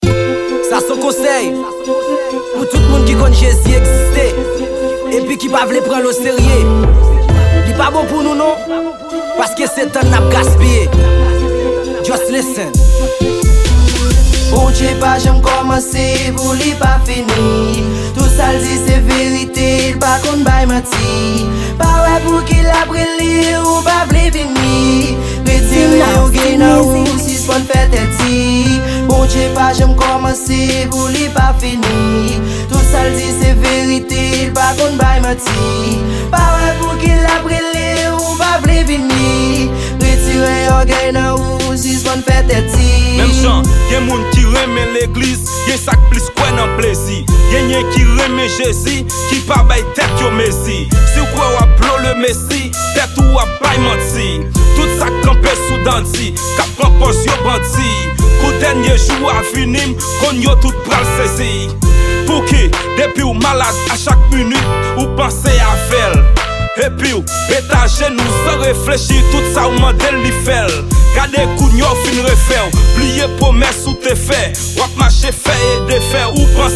Genre: Rap-Evangelique.